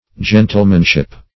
Gentlemanship \Gen"tle*man*ship\, n.
gentlemanship.mp3